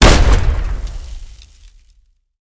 explosionSmallNear3.ogg